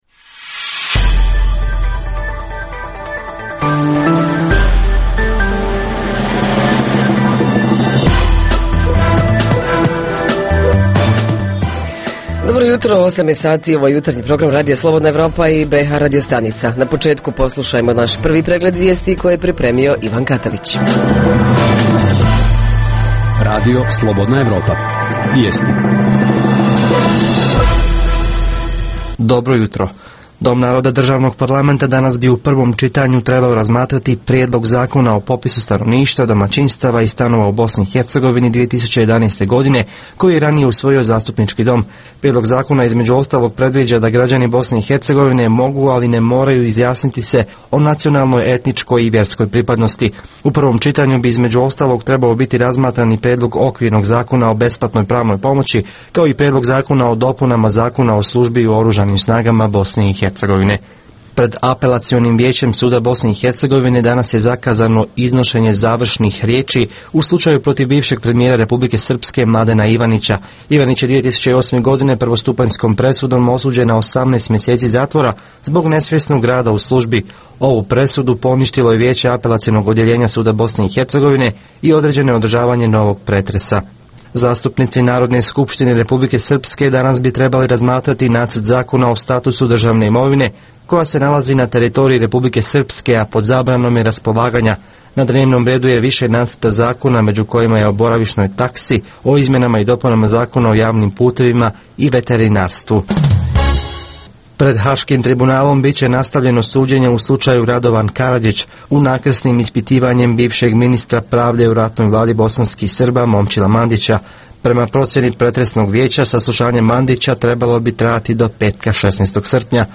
Ljetna prehrana - šta jesti da se vrućine lakše podnesu? Reporteri iz cijele BiH javljaju o najaktuelnijim događajima u njihovim sredinama.
Redovni sadržaji jutarnjeg programa za BiH su i vijesti i muzika.